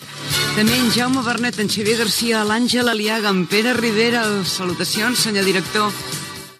Comiat del programa amb els noms de l'equip
FM